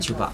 [t͡ɕupa] noun ashes